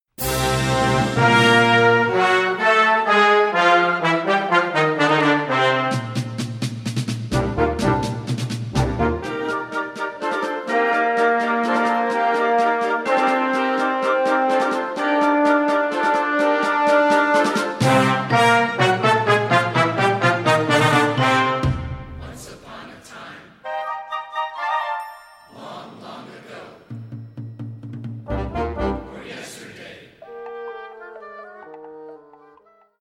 Sous-catégorie Musique de concert
Instrumentation Ha (orchestre d'harmonie)